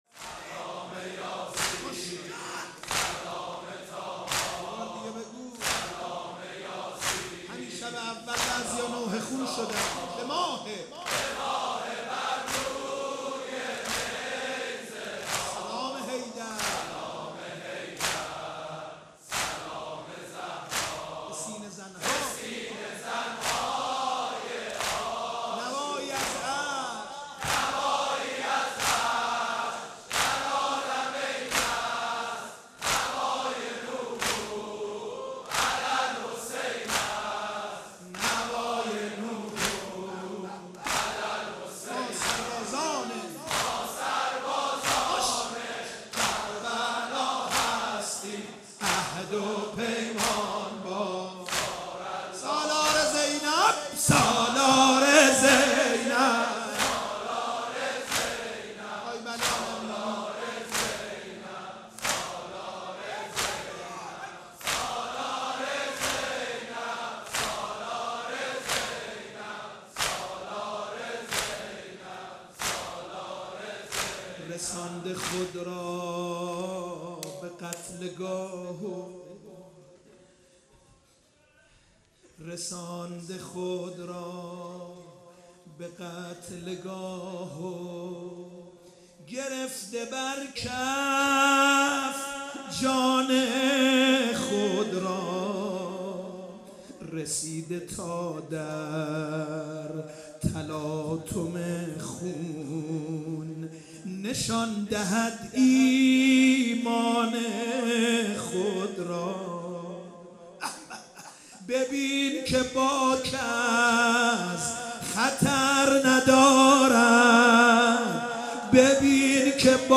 واحد | سلام یاسین سلام طاها
مداحی
در شب پنجم محرم 1439 | هیأت رزمندگان اسلام قم